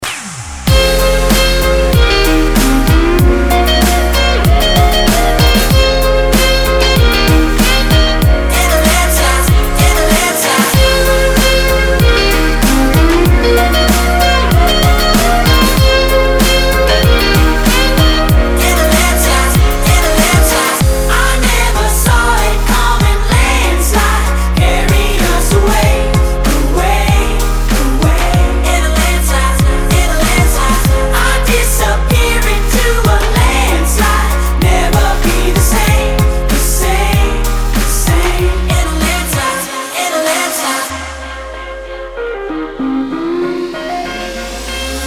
• Качество: 320, Stereo
Electronic
инструментальные
электрогитара